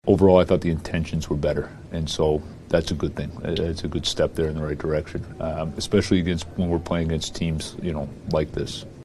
After losing to Calgary, 2-1 in Pittsburgh on January 10th, Muse says the Pens played better against the defensive-minded Flames.